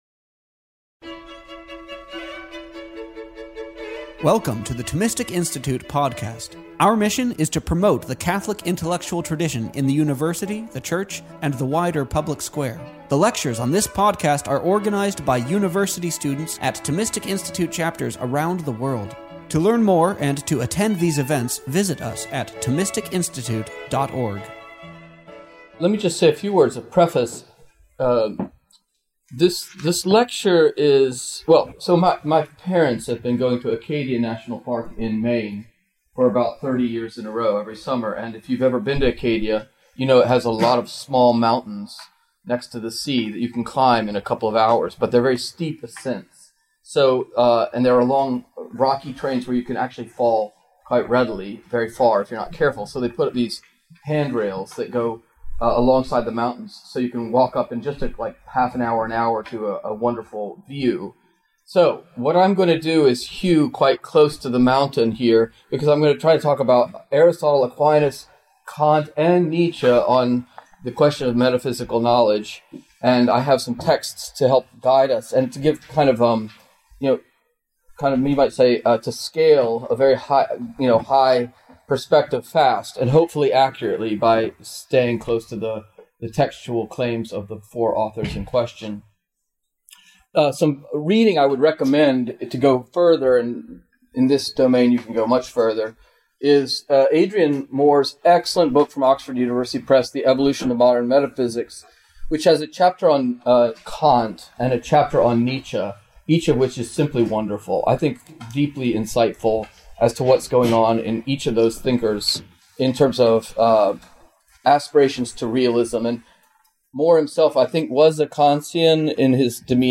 This talk was offered at Southern Methodist University on April 11th, 2019.